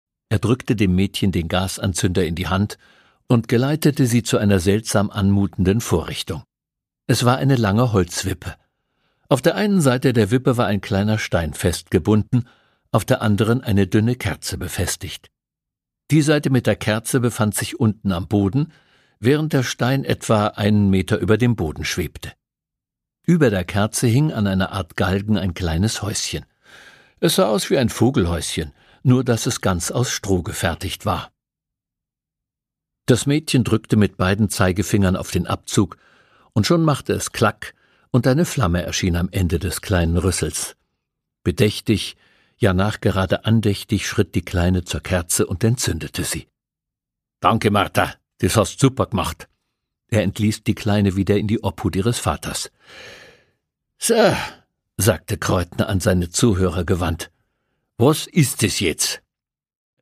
Produkttyp: Hörbuch-Download
auf unnachahmliche Weise bajuwarisch.